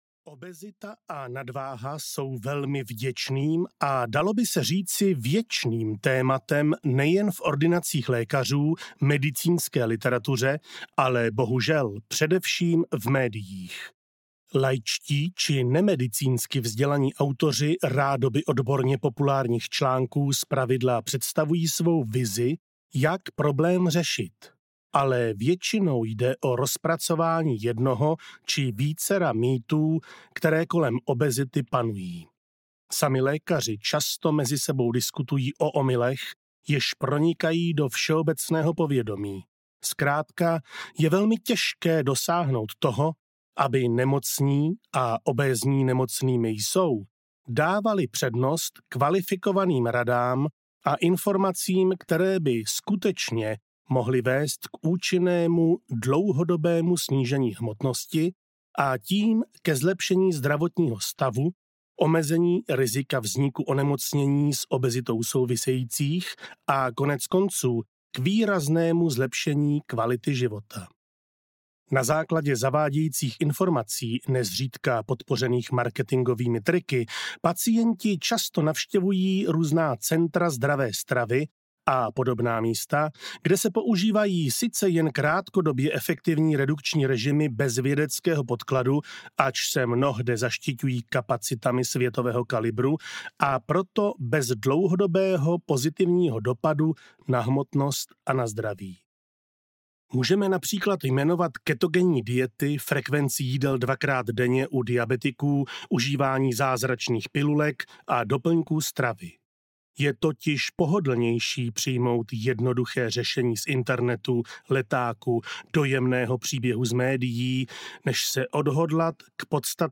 Manuál úspěšného hubnutí audiokniha
Ukázka z knihy